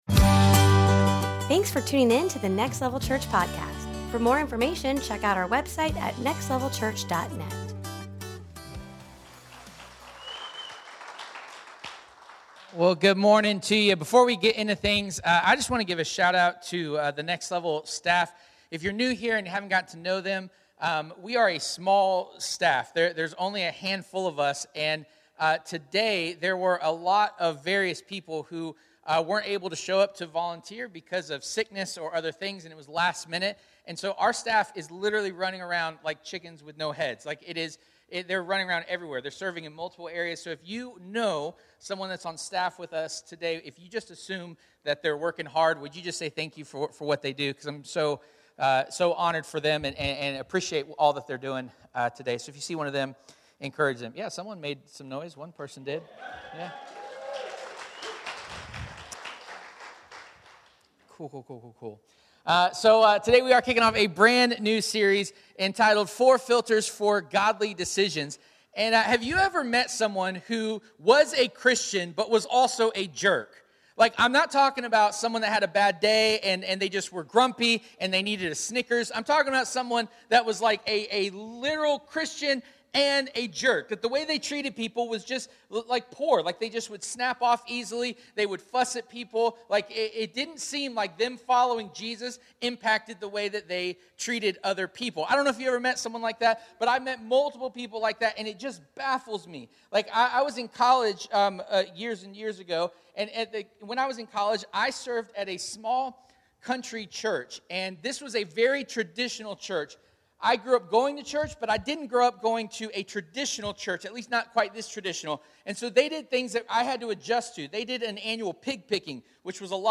4 Filters for Godly Decisions Service Type: Sunday Morning Everyone uses a filter to process their decisions.